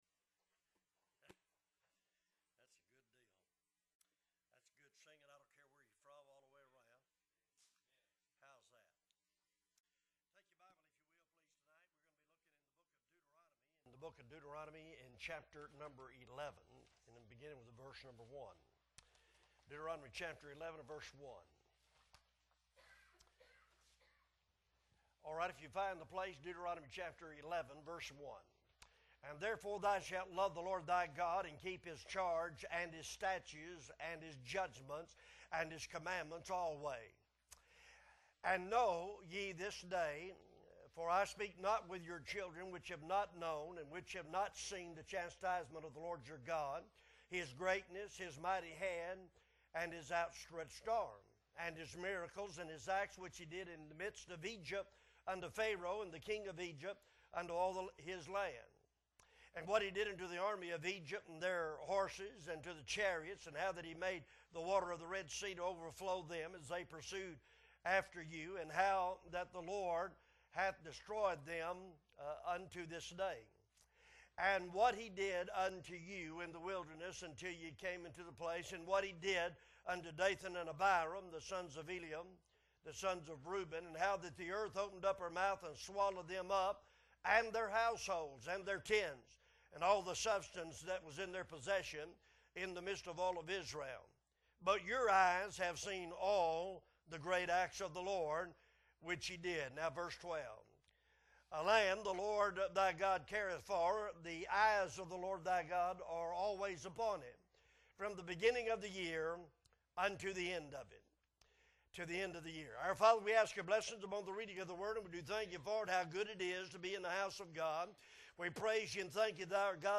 January 4, 2022 Wednesday Service